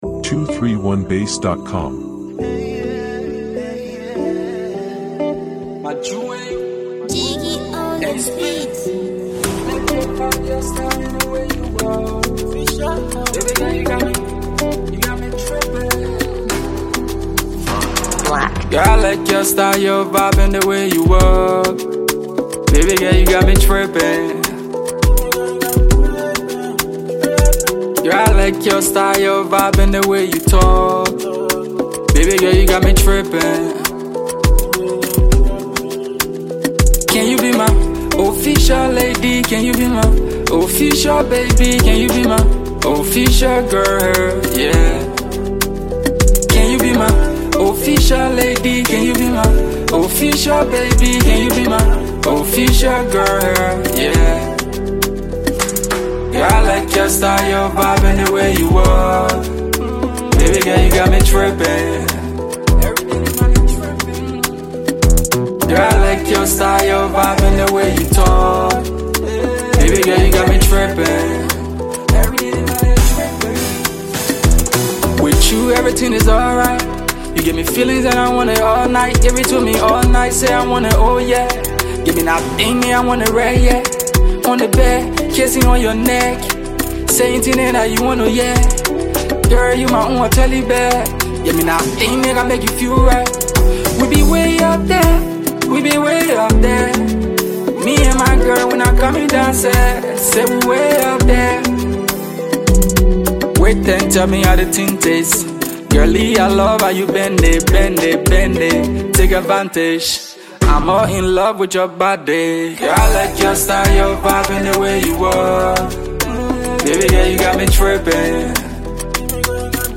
swoon-worthy love anthem